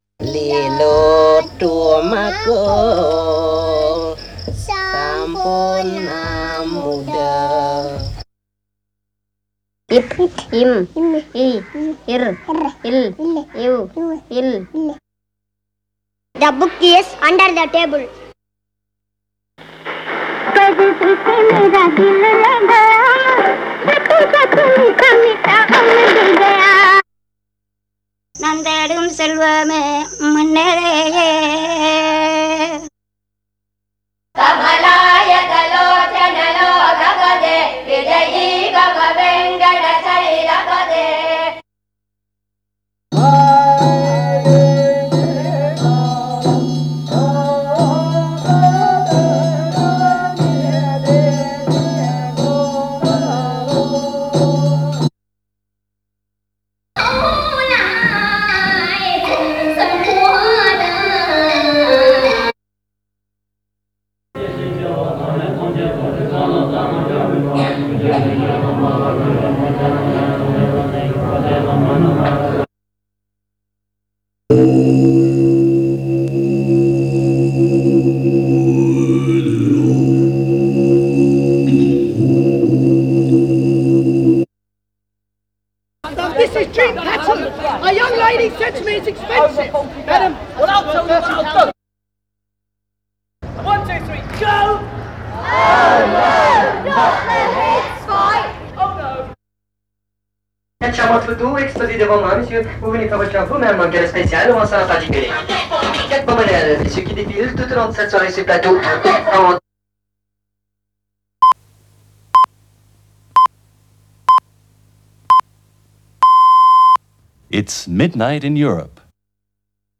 34 Ethnic Vocals.wav